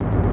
TM-88 Percs [Roll].wav